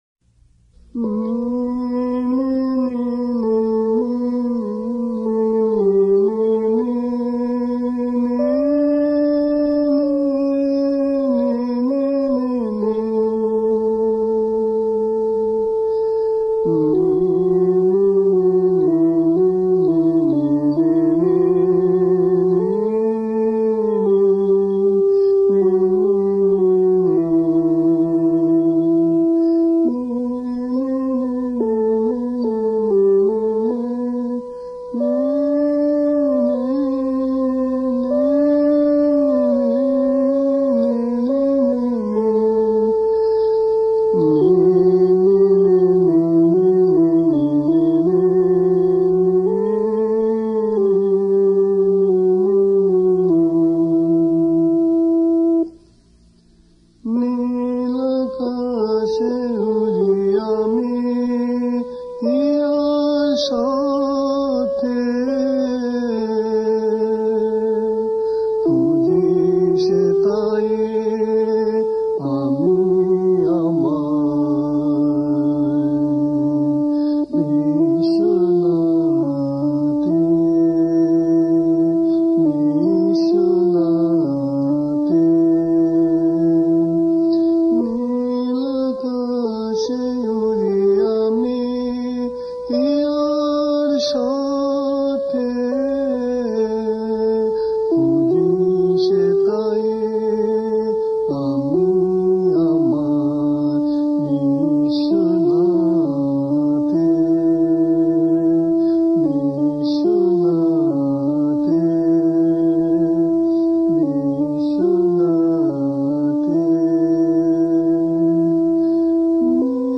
soulful and illumining offering